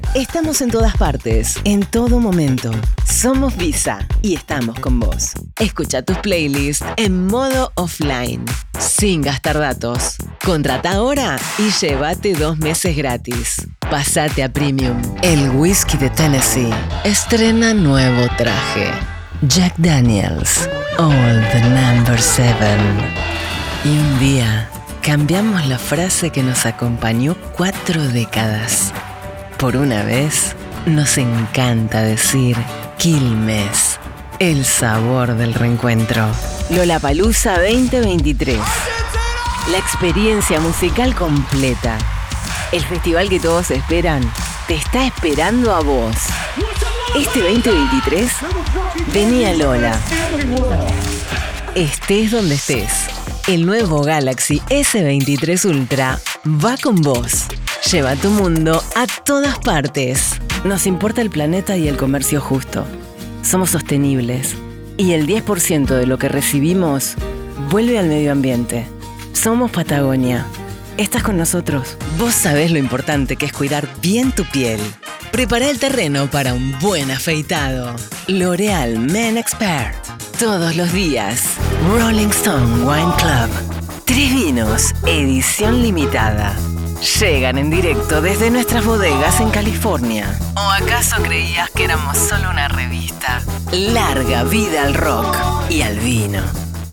Demo Argentina
Me encanta grabar para toda Latinoamérica y siempre es un placer volver a la locución comercial para Argentina.
Paisajes sonoros que me llevan a explorar diferentes ritmos, velocidades y proxemias.